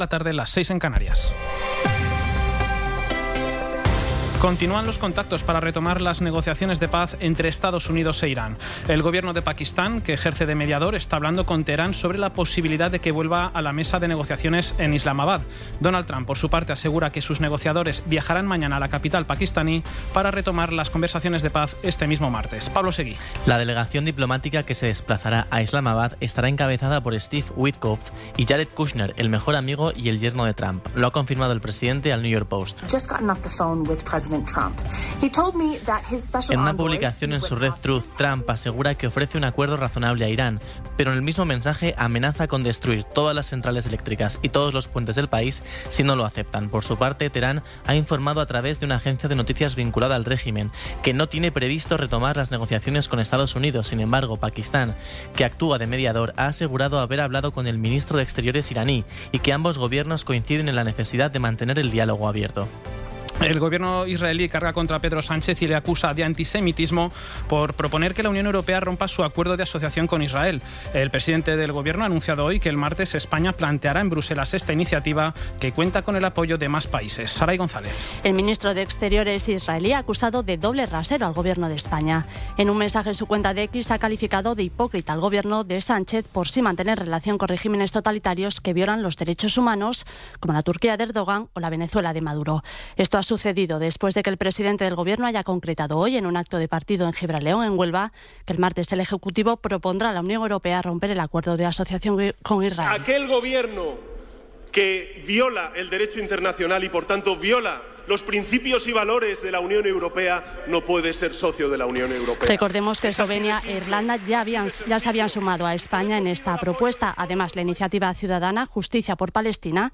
Resumen informativo con las noticias más destacadas del 19 de abril de 2026 a las siete de la tarde.